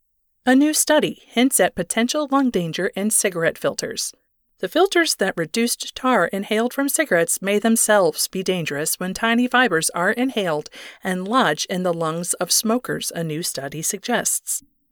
Character - Carol Stanley
Radio character - Sample.mp3